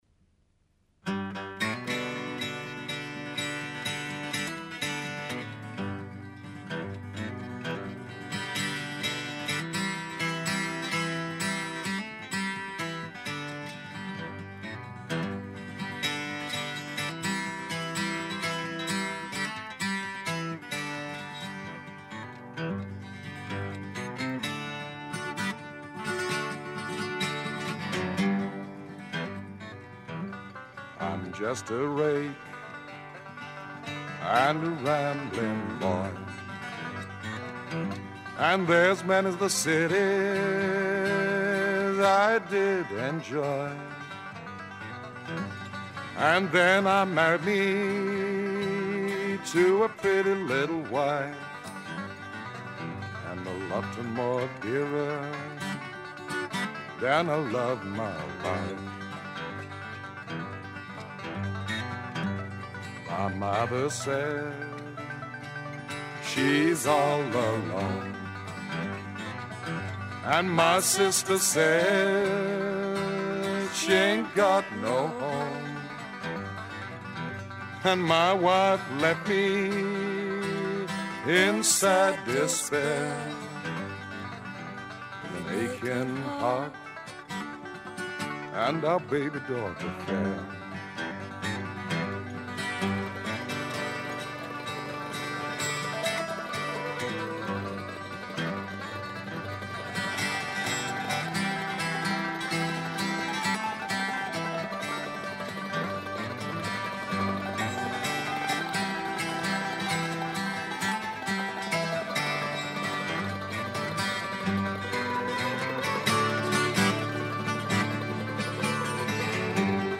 John does a acoustic version of the traditional folk song 'Rake and A Rambling Boy' which in many ways is semi-autobiographical and Bo Diddley's 'Let Me Pass'.